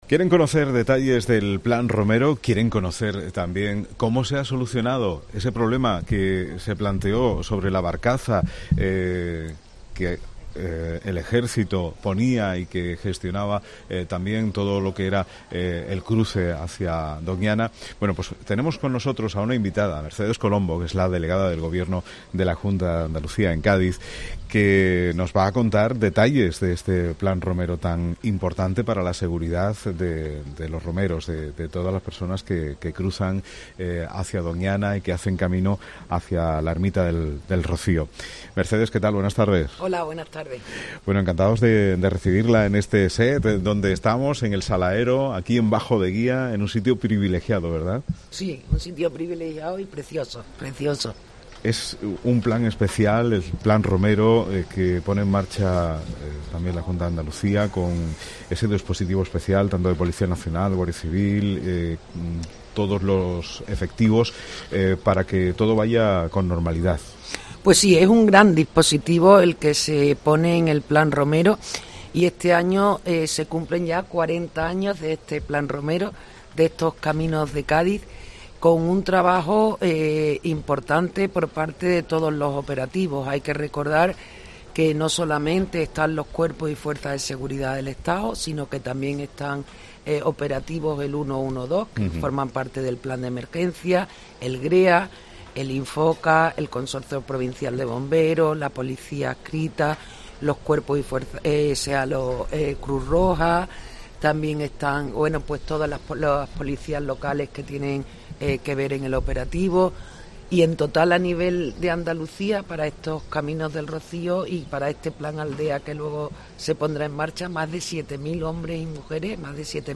Mercedes Colombo, Delegada del Gobierno de la Junta de Andalucía en Cádiz ha ofrecido novedades del Plan Romero 2024.
Mercedes Colombo, Delegada del Gobierno de la Junta de Andalucía en Cádiz - ROMERÍA ROCÍO 2024